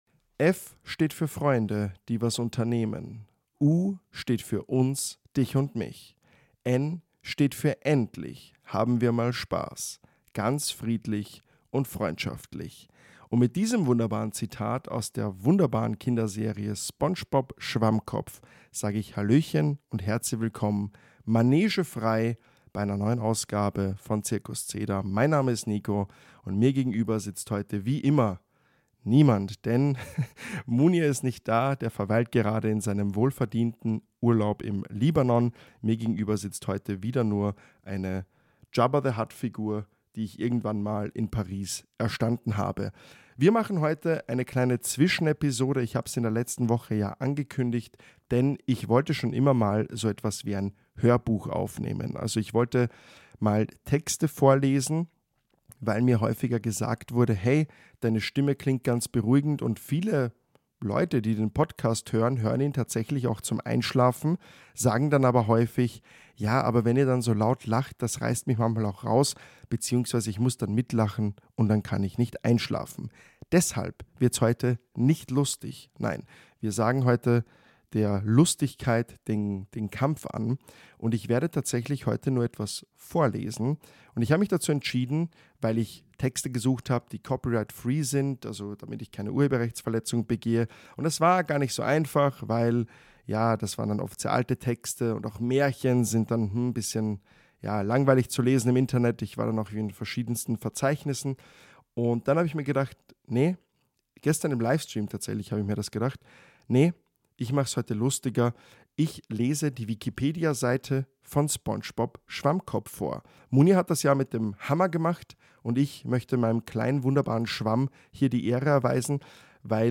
Er liest euch den Wikipedia-Artikel zu Spongebob Schwammkopf vor und möchte damit eine kleine Einschlafhilfe sein, sofern diese denn benötigt wird.